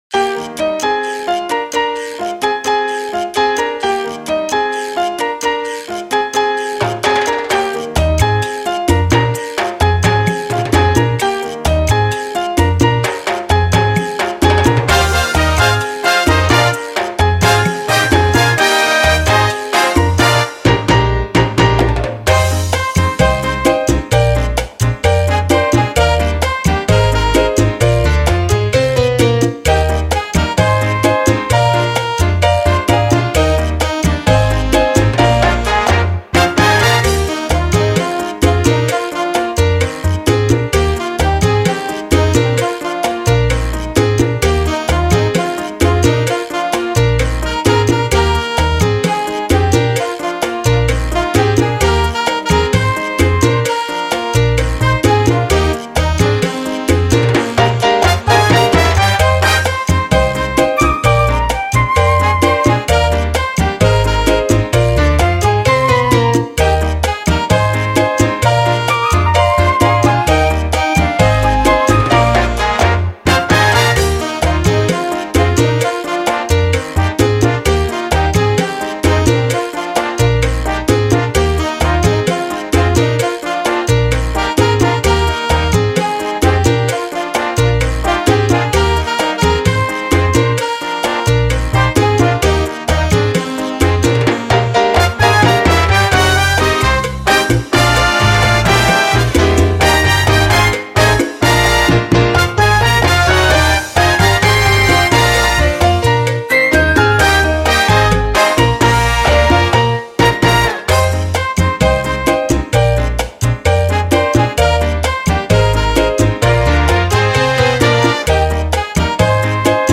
Versió instrumental